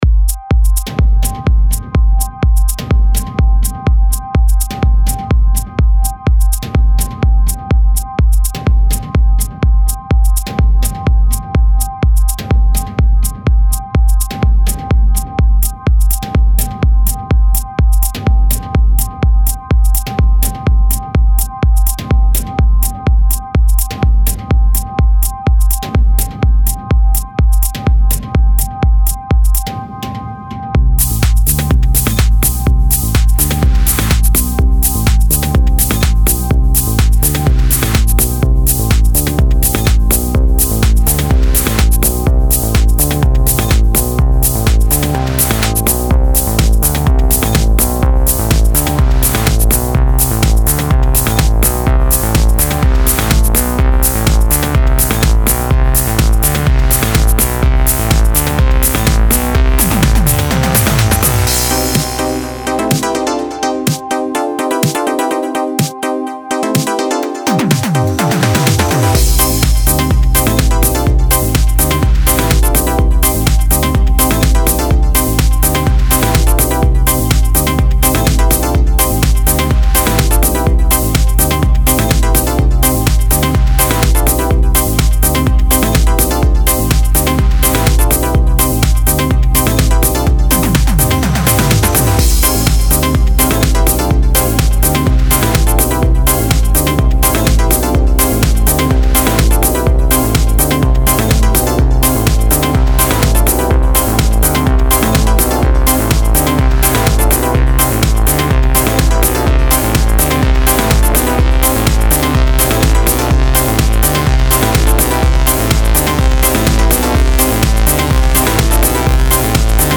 • Classic analog warmth meets ultra-modern features